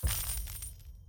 get_coins.ogg